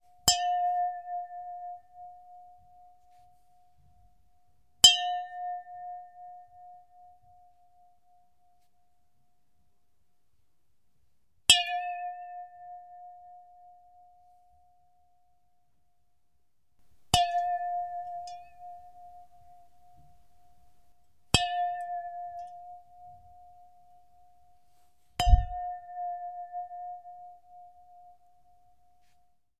Pint Glass Hits 2
chime ding glass hit ping pint ring tap sound effect free sound royalty free Sound Effects